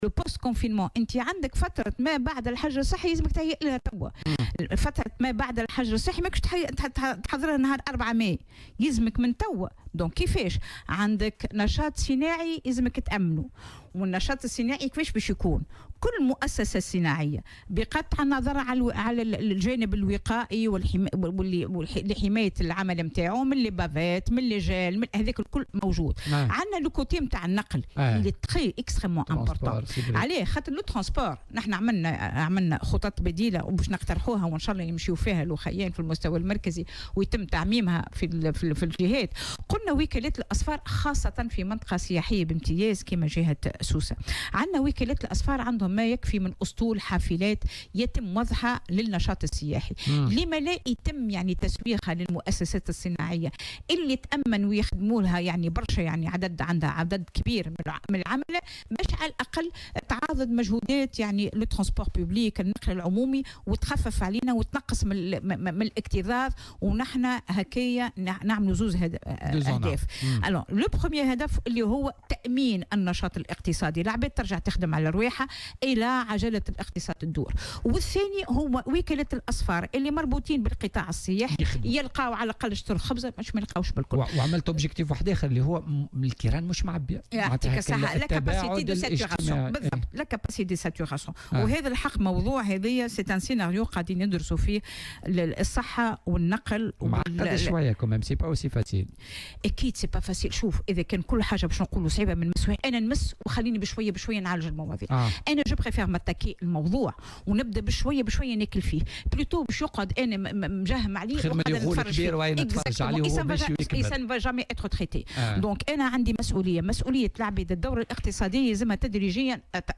وأضافت في مداخلة لها اليوم في برنامج "بوليتيكا" أنه إلى جانب اتخاذ جملة من التدابير الوقائية بالنسبة للعمّال والموظفين على غرار الحرص على استعمال "الكمامات" و"الجال" المعقّم وغيرها، يجب التفكير في إيجاد حلول بديلة لتخفيف الضغط على النقل العمومي، خاصة بالنسبة للمؤسسات الصناعية التي تشغّل عددا كبيرا من العملة.